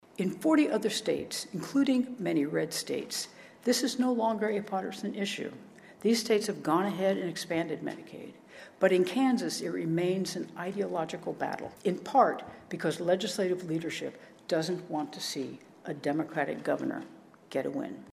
Kansas Governor Laura Kelly delivered the Landon Lecture at Kansas State University on Friday.